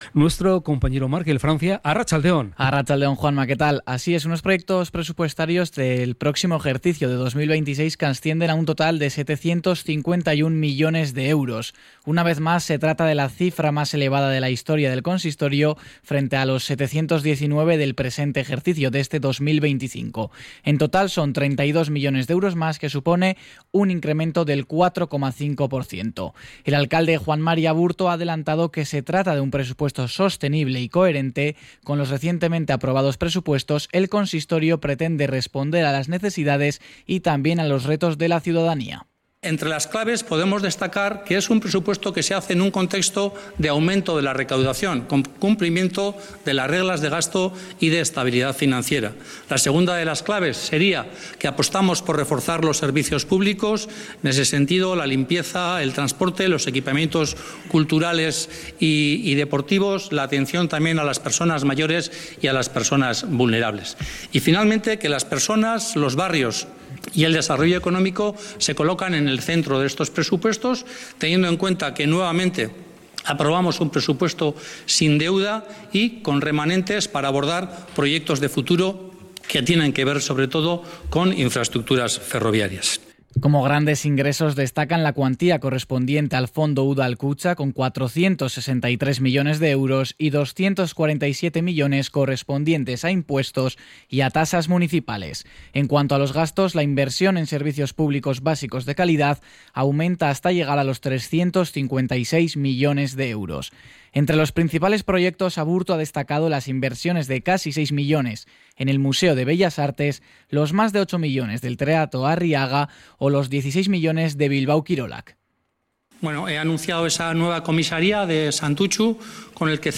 cRONICA-PRESUPUESTOS.mp3